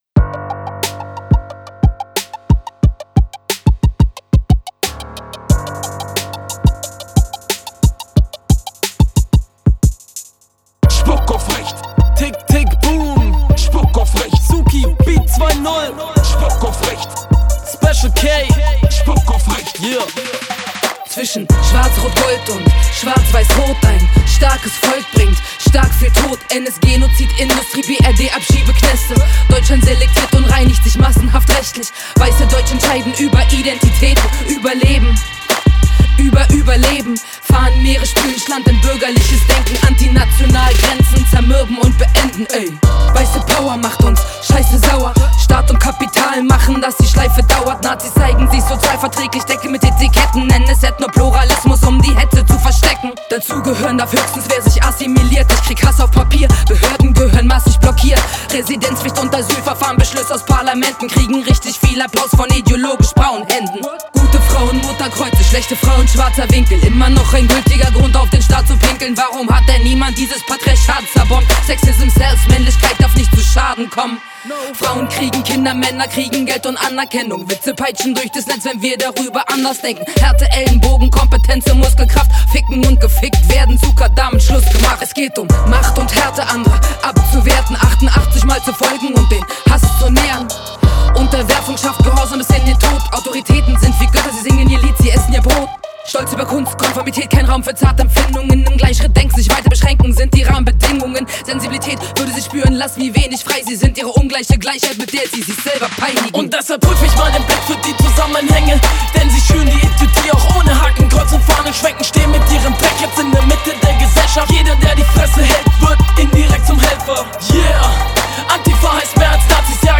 Zeckenrap